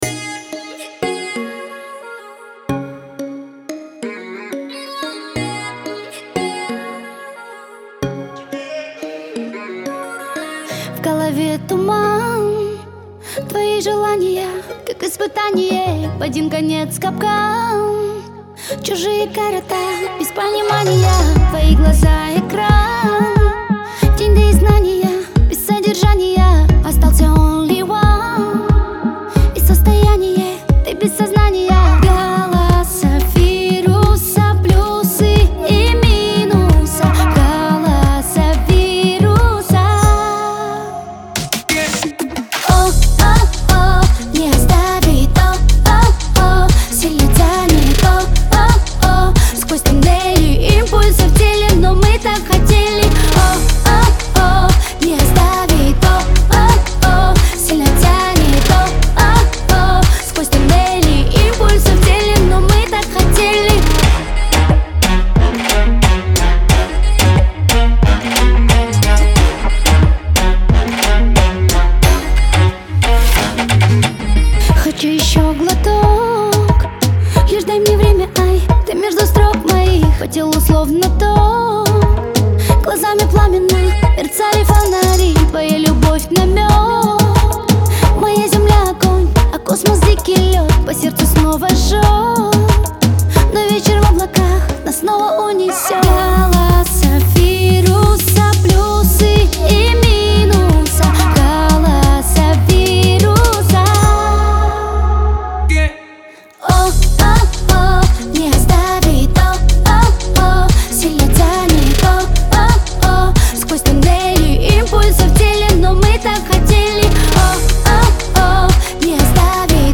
Звучание отличается мягкими мелодиями и душевным вокалом